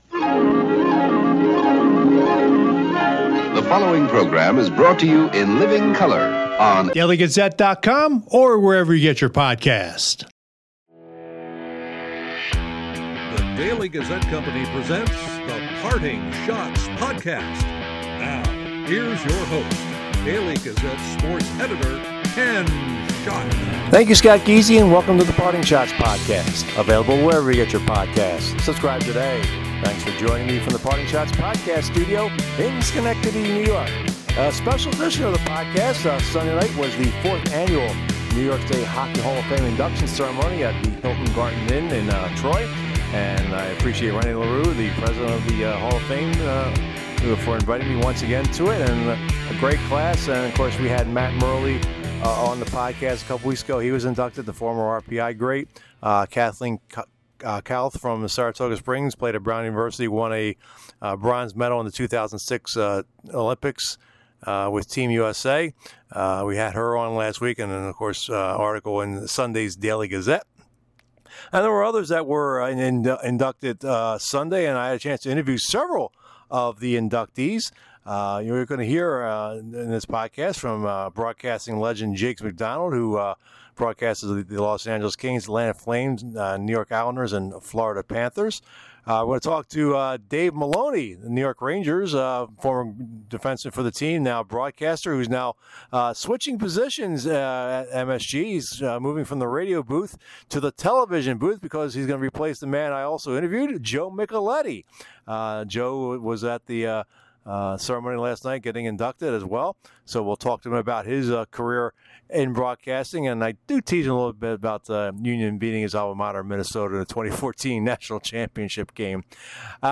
Interviews from Sunday's New York State Hockey Hall of Fame induction ceremony